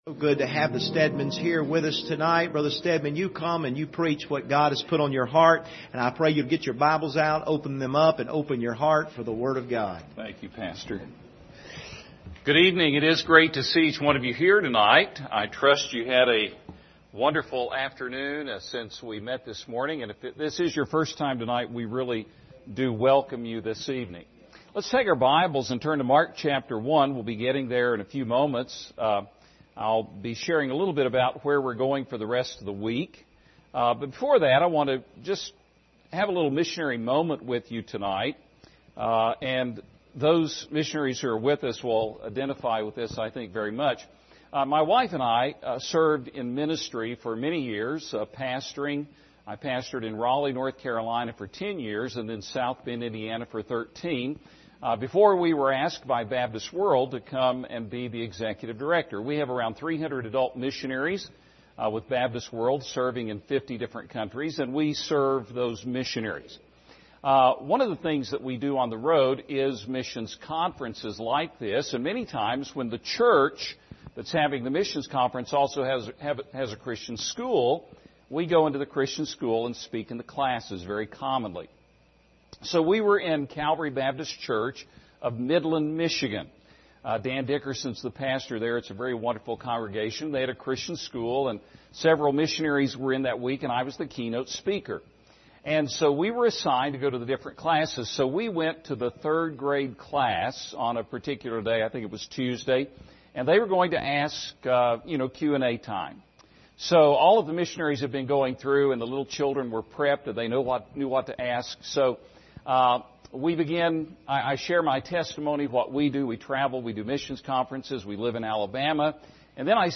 Series: 2020 Missions Conference
Service Type: Special Service